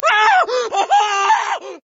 scream4.ogg